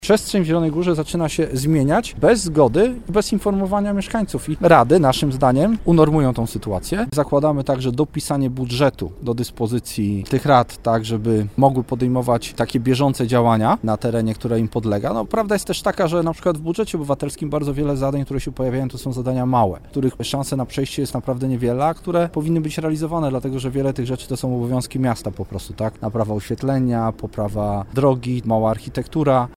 O swoim pomyśle działacze SLD poinformowali dziś na konferencji prasowej.